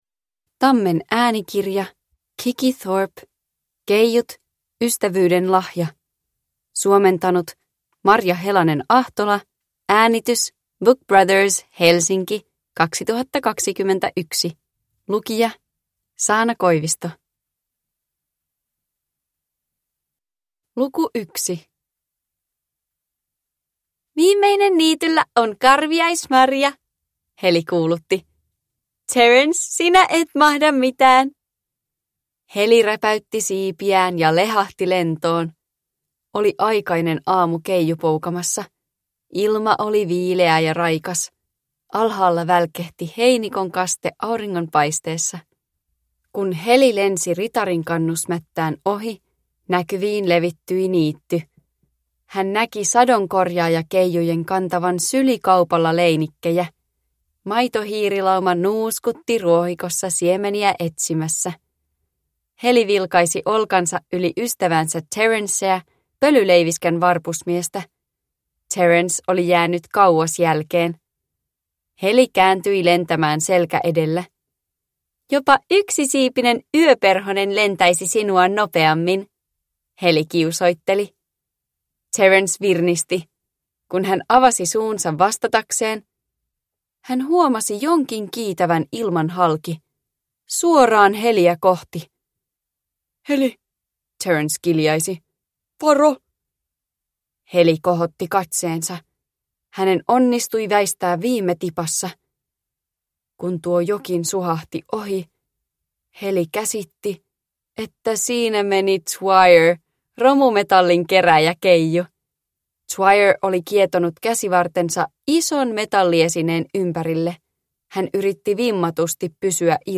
Keijut. Ystävyyden lahja – Ljudbok – Laddas ner